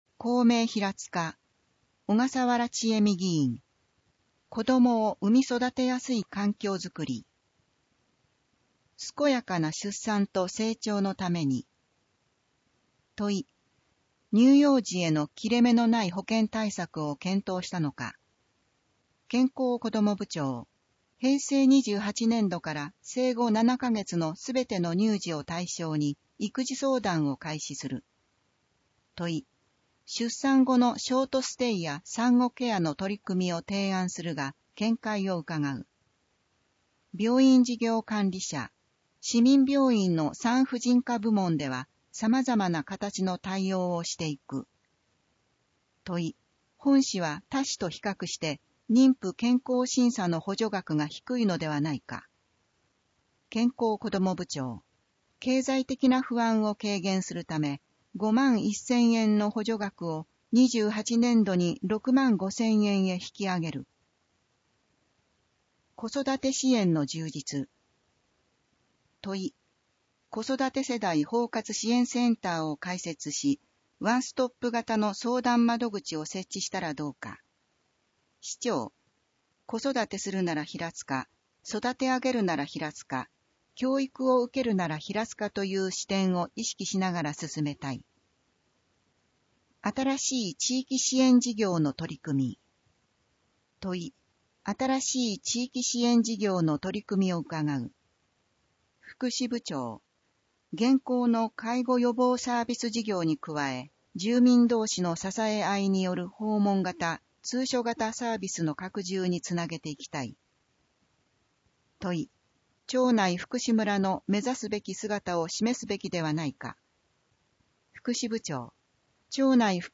平塚市議会では目の不自由な方に、ひらつか議会だよりを音声化した「声の議会だより」と、掲載記事を抜粋した「点字版議会だより」をご用意しています。
「声の議会だより」は平塚市社会福祉協議会と平塚音訳赤十字奉仕団の協力により作成しています。